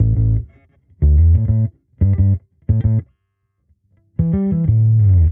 Index of /musicradar/sampled-funk-soul-samples/90bpm/Bass
SSF_PBassProc1_90A.wav